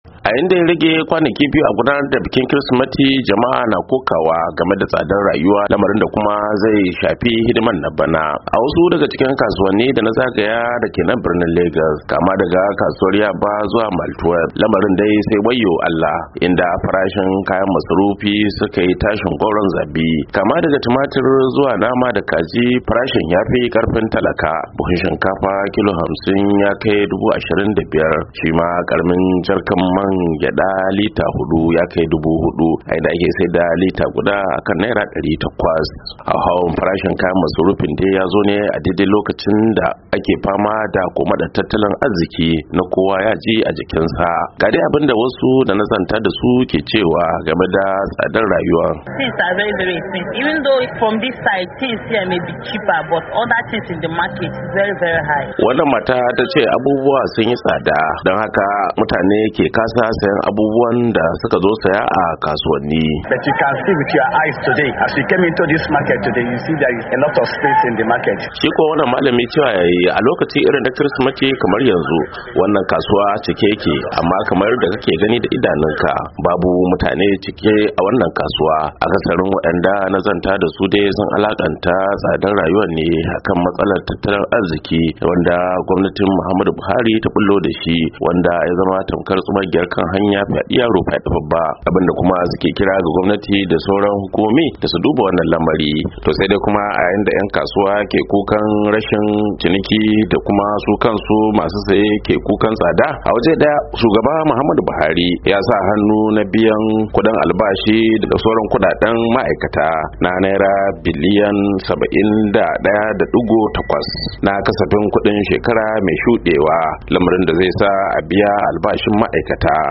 Labari da Dumi-Duminsa